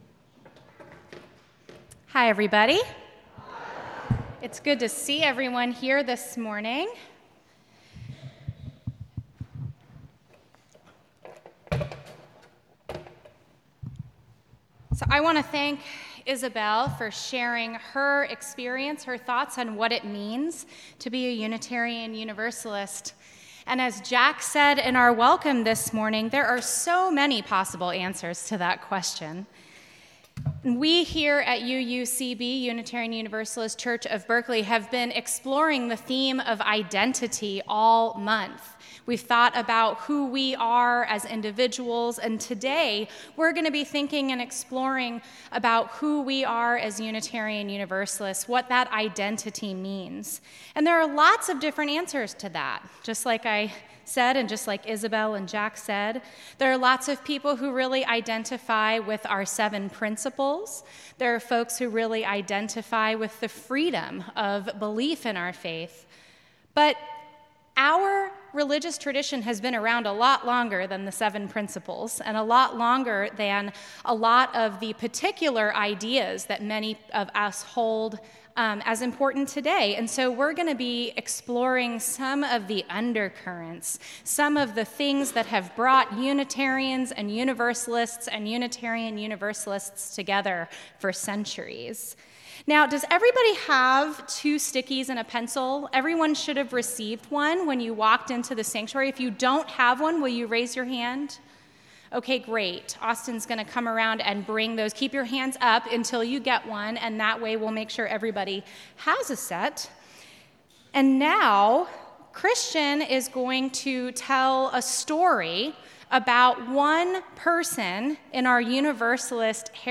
This is a service for all ages!